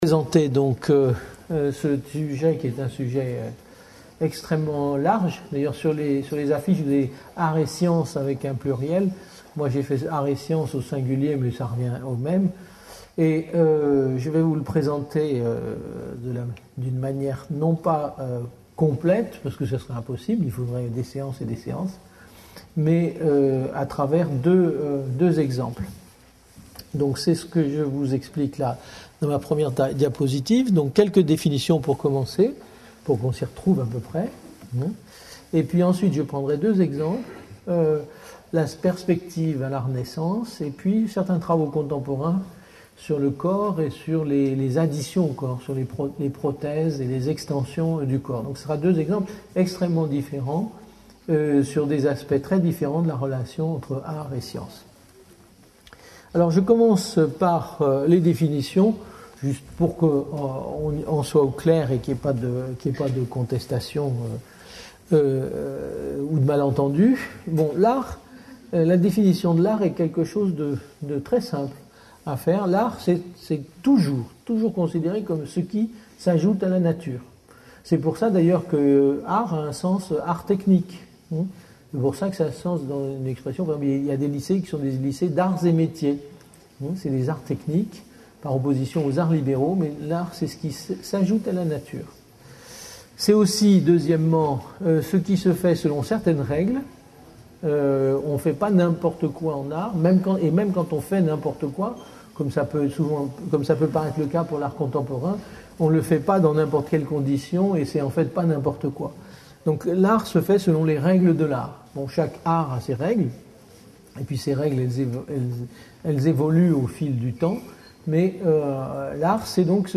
Une conférence de l'UTLS au Lycée : Art et science par Yves MichaudLycée Val de Garonne à Marmande (47)